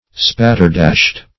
Search Result for " spatterdashed" : The Collaborative International Dictionary of English v.0.48: Spatterdashed \Spat"ter*dashed`\, a. Wearing spatterdashes.
spatterdashed.mp3